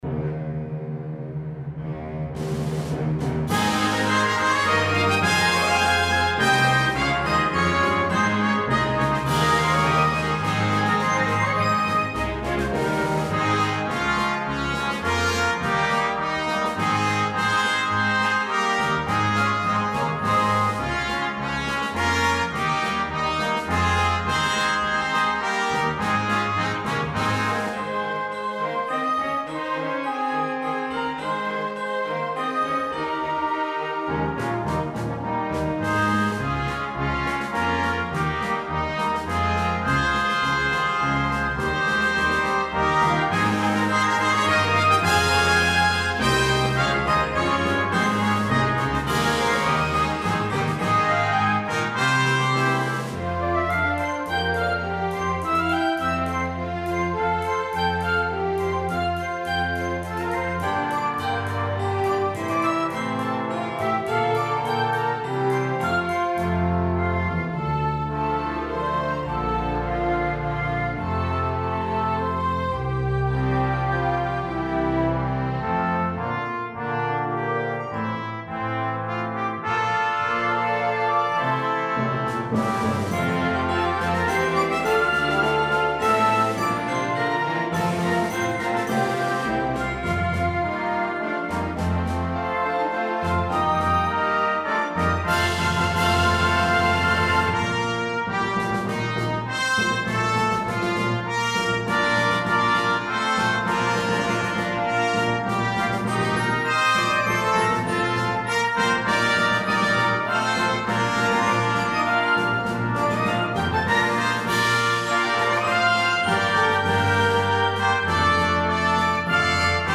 The setting is triumphant and energetic.